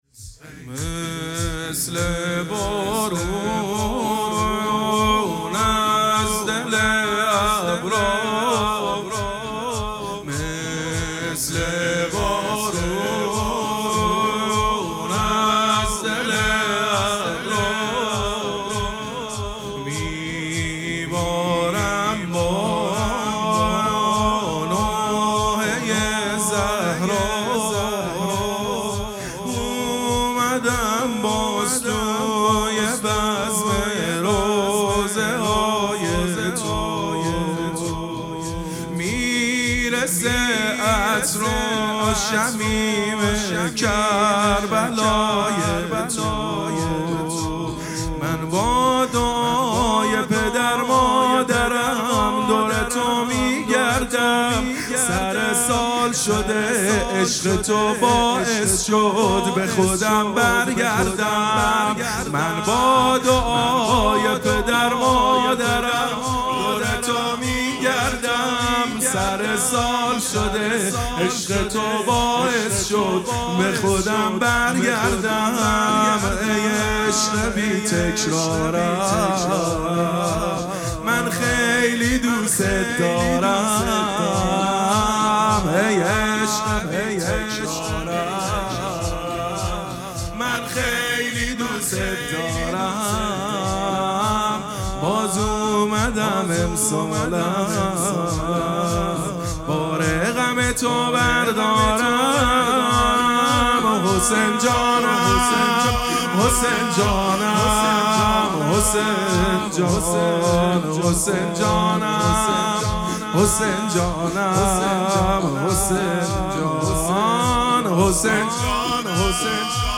پیش زمینه مثل بارون